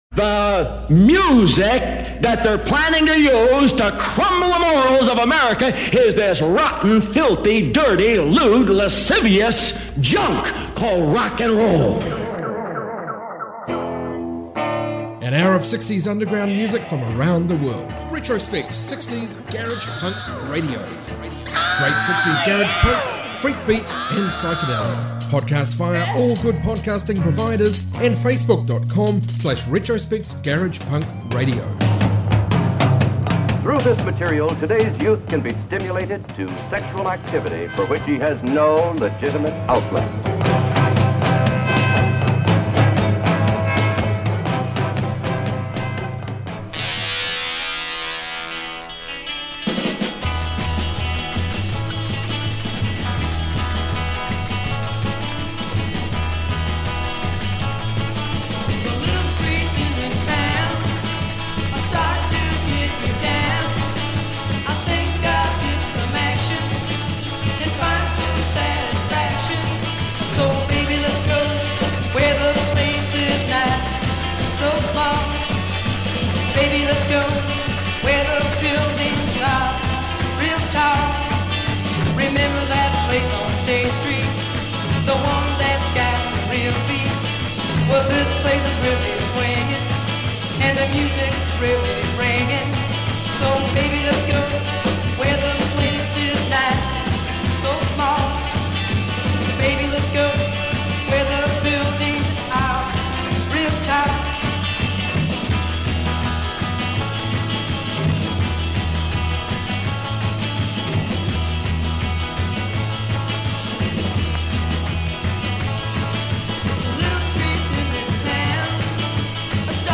60s garage rock garage punk freakbeat